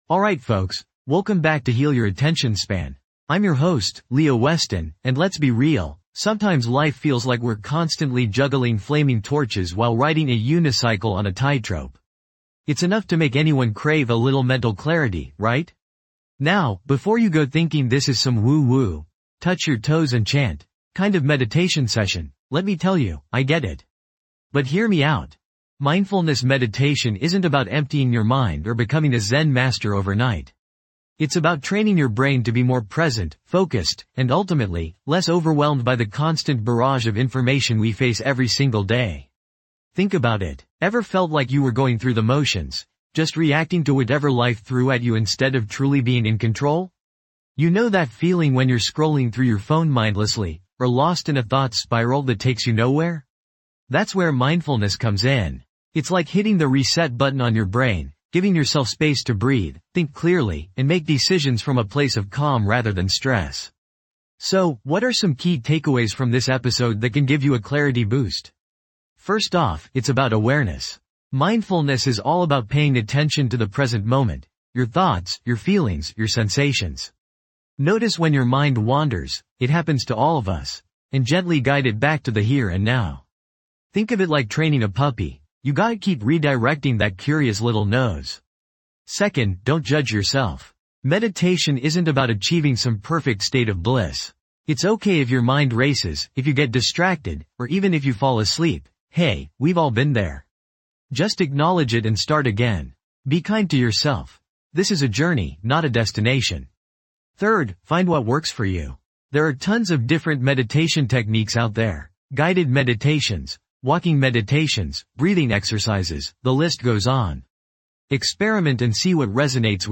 Discover the transformative power of Mindfulness Meditation as we guide you through a focused practice designed to boost clarity and sharpen mental focus.
This podcast is created with the help of advanced AI to deliver thoughtful affirmations and positive messages just for you.